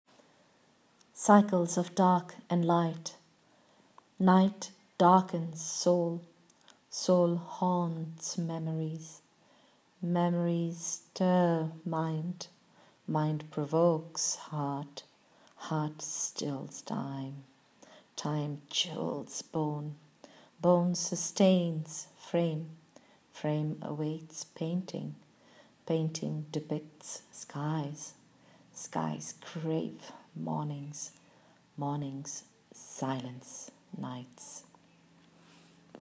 Reading of the poem